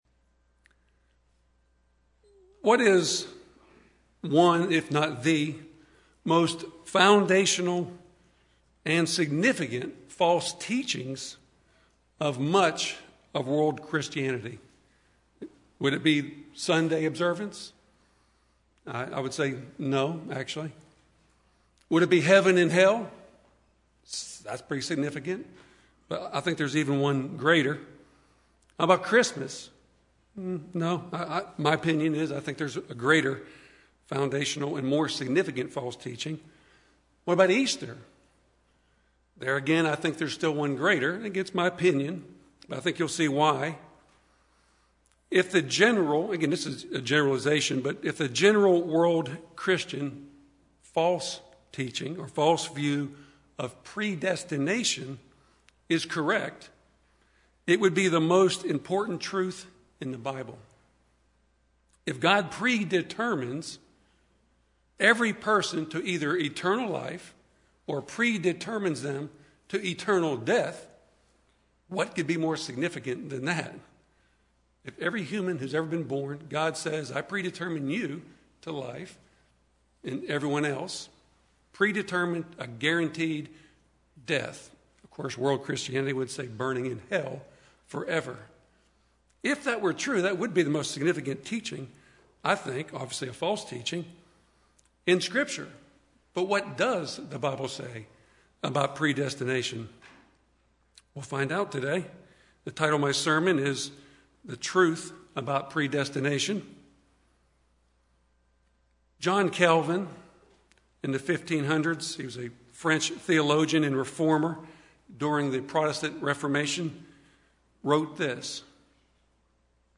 This word-study sermon looks at the meaning of "predestination" as it's used in the scriptures to describe God's relationship to His called and chosen people.